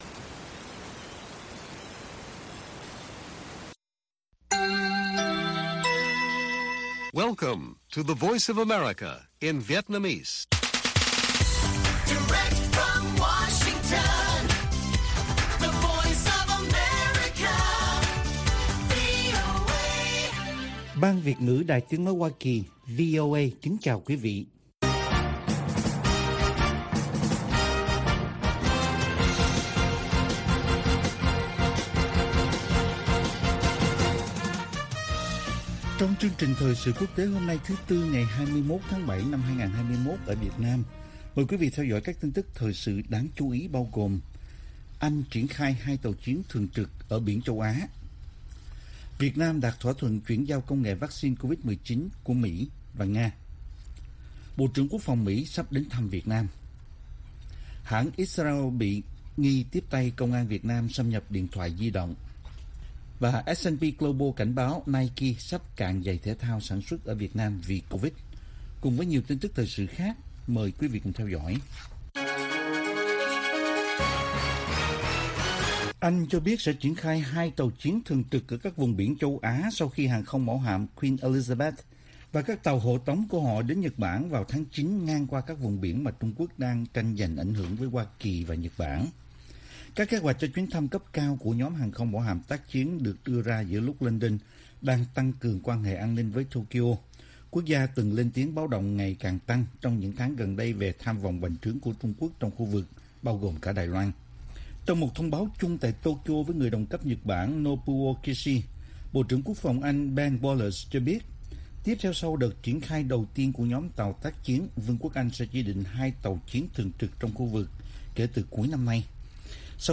Bản tin VOA ngày 21/7/2021